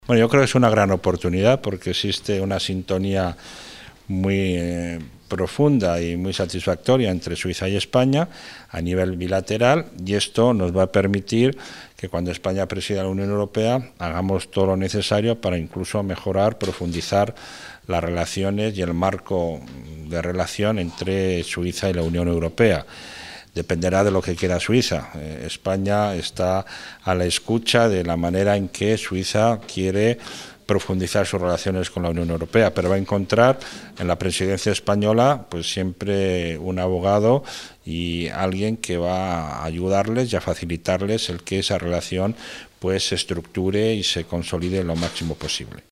Miguel Ángel Moratinos, ministro de Exteriores de España, en entrevista con swuissinfo.